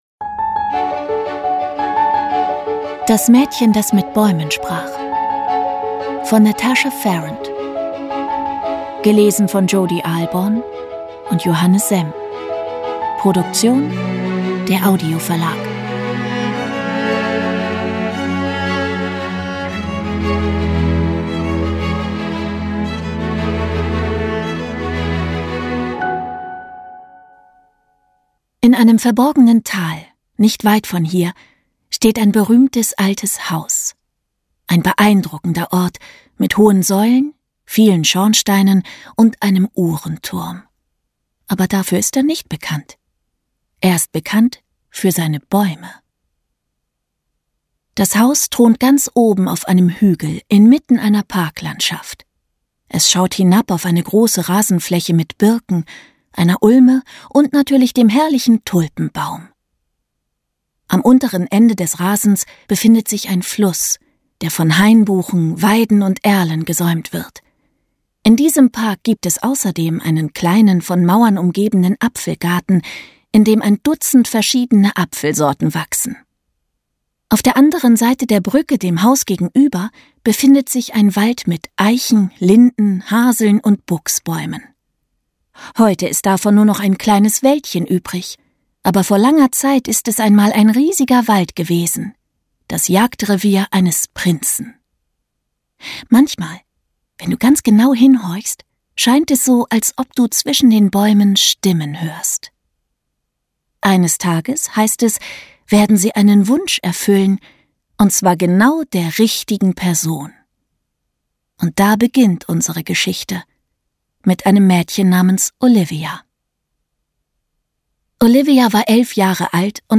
Ungekürzte Lesung mit Musik